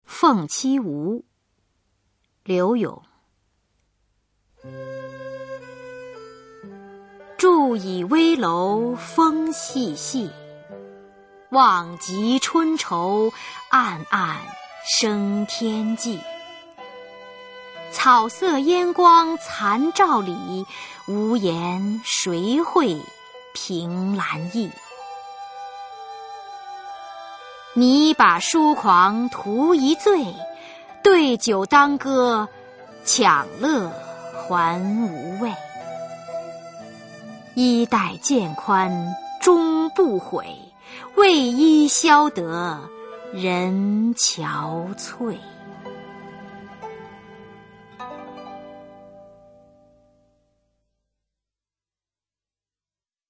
[宋代诗词朗诵]柳永-凤栖梧 古诗词诵读